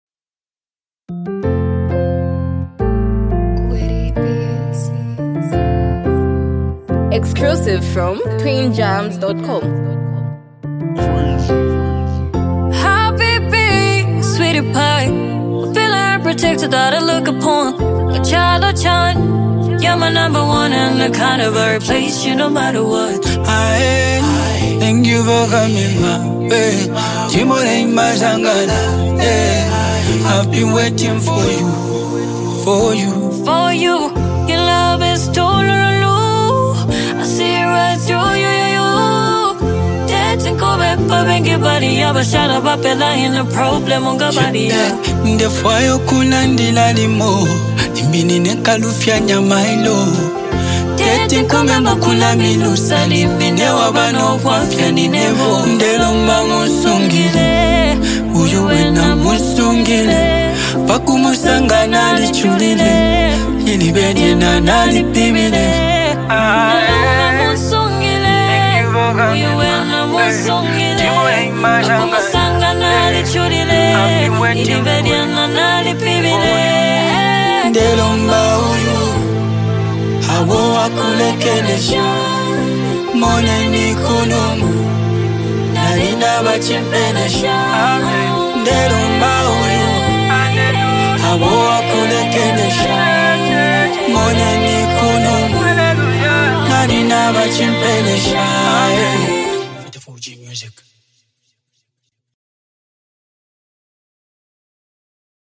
a bold anthem about choosing you over everything else.